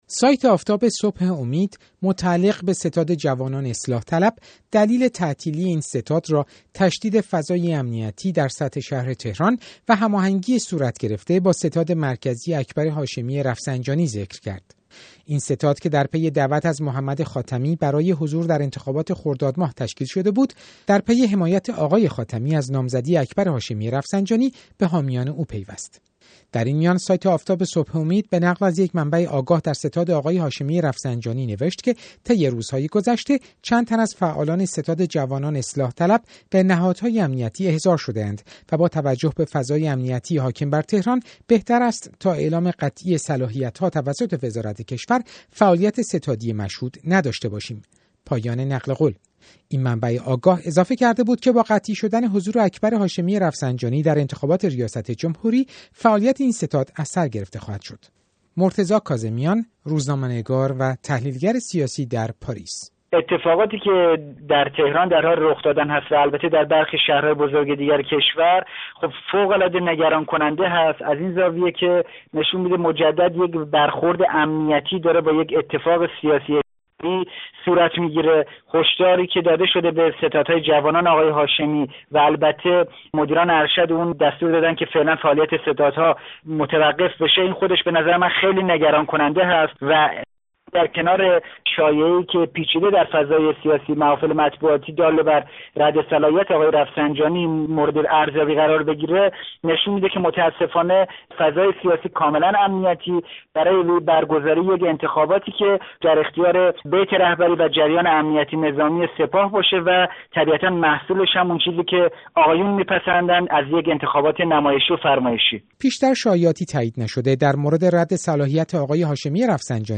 تحلیل
در گفت‌وگو